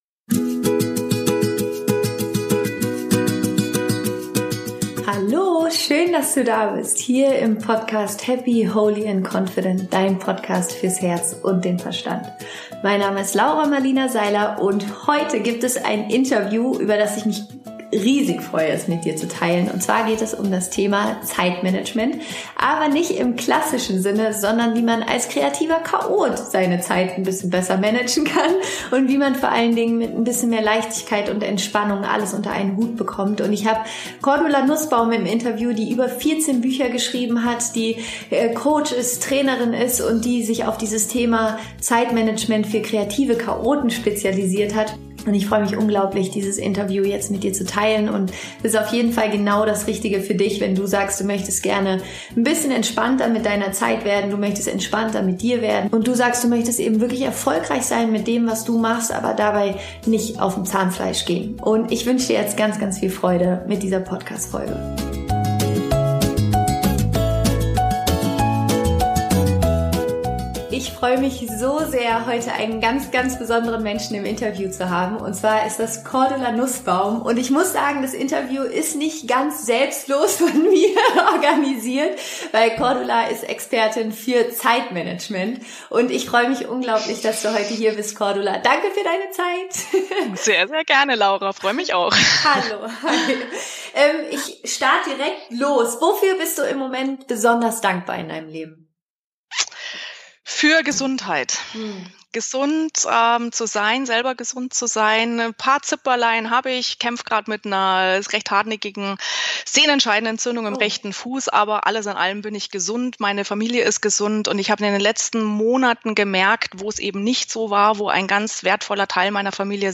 Ich spreche im Interview